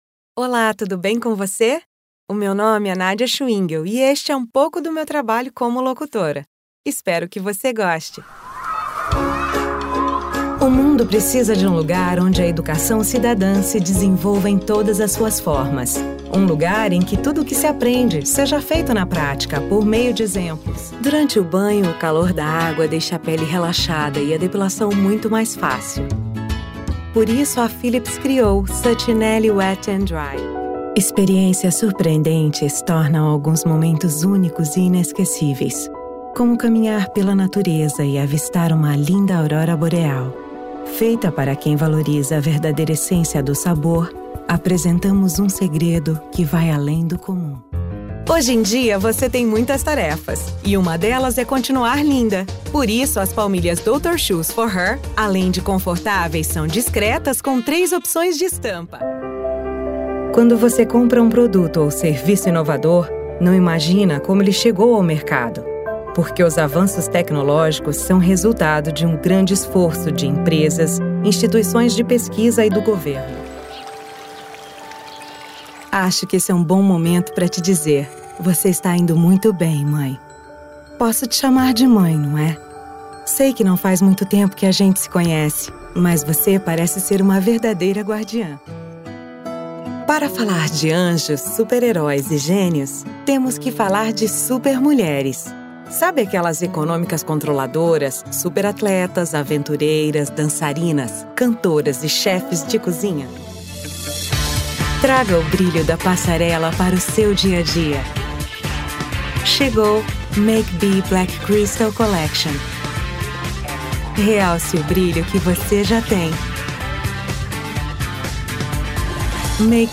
Sprechprobe: Werbung (Muttersprache):
Professional, above all, but confident, friendly, reliable, friendly, cheerful and sophisticated. Which can be serious, sexy and very funny.
Lots of positive energy, even for a serious commercial or narrative.
Recording in Brazilian Portuguese and American English with a slight accent. I work from my studio with acoustic treatment and top-tier equipment providing high standard in audio quality and exquisite care in finalization and delivery.